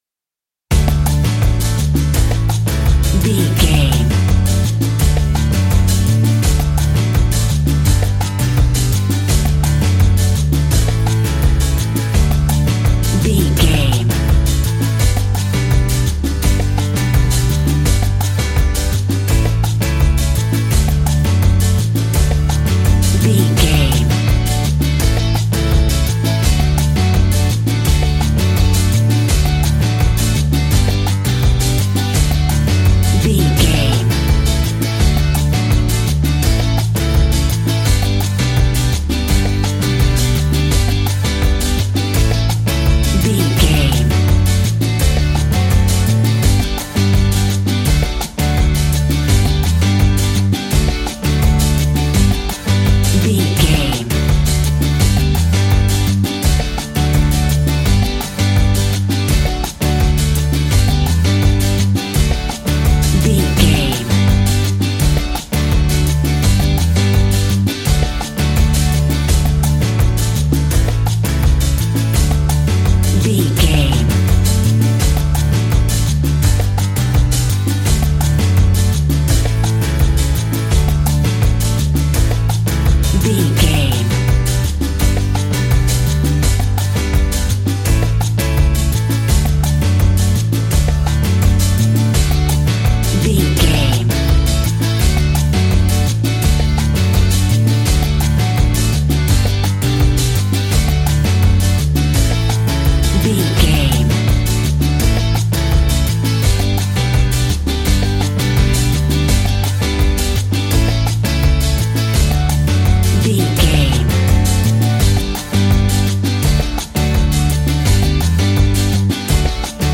That perfect carribean calypso sound!
Uplifting
Ionian/Major
steelpan
worldbeat
drums
bass
brass
guitar